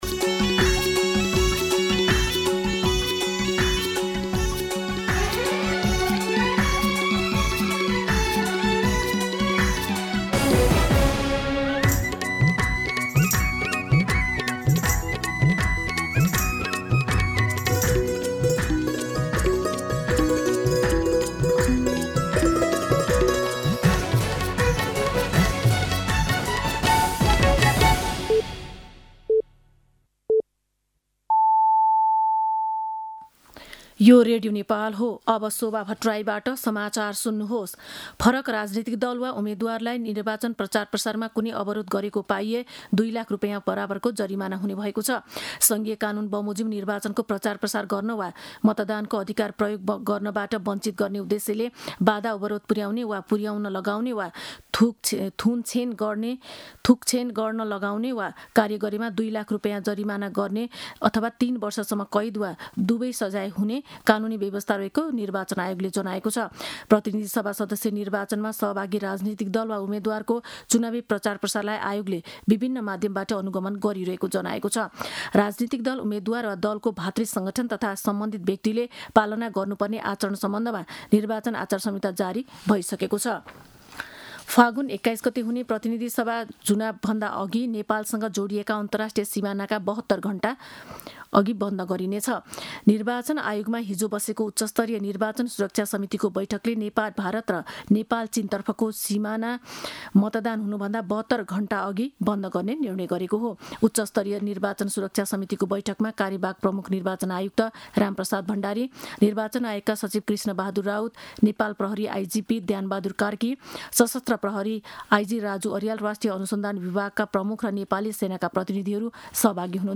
मध्यान्ह १२ बजेको नेपाली समाचार : १३ फागुन , २०८२